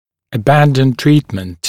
[ə’bændən ‘triːtmənt][э’бэндэн ‘три:тмэнт]прекращать лечение, отказаться от лечения (со стороны врача)